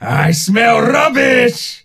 ash_start_vo_02.ogg